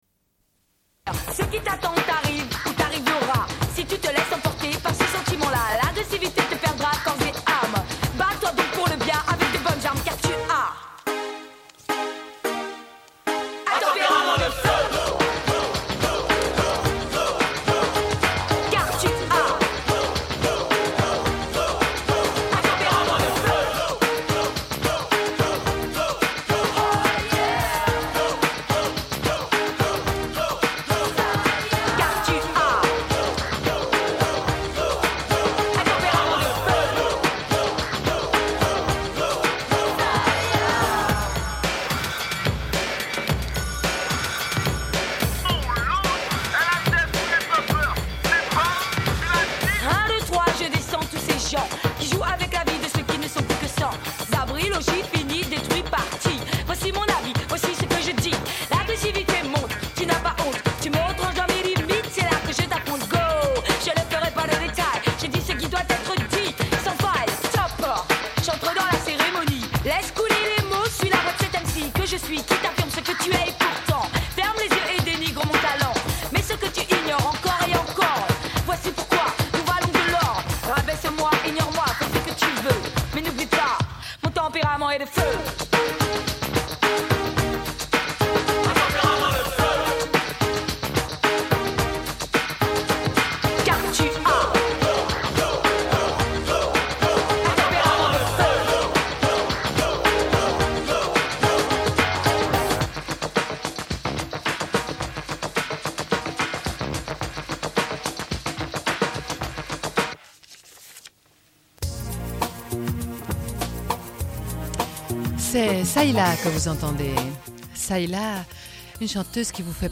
Une cassette audio, face A31:25